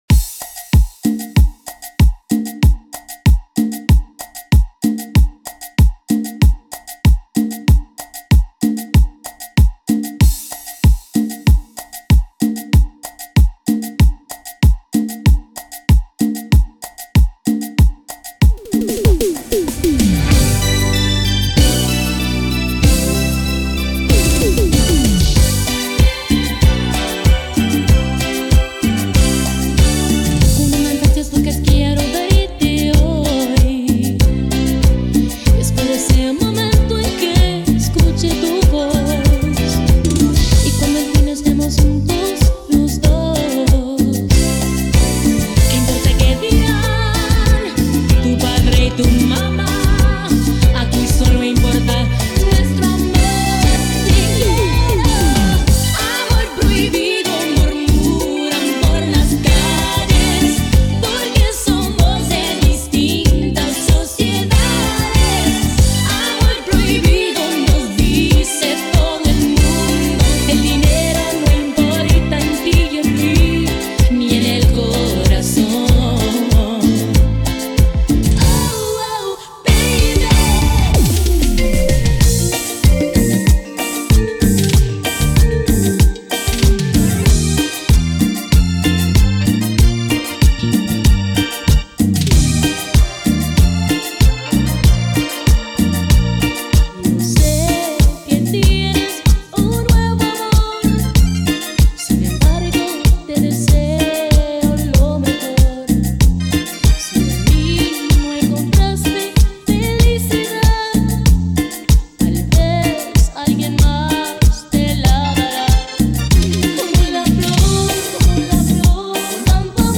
with a Miami Dance Vibe
a lively remix by Miami’s DJ & VJ
This energetic minimix
modern house and club beats